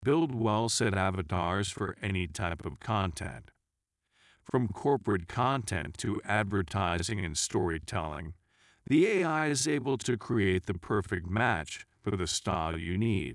Slow Down Audio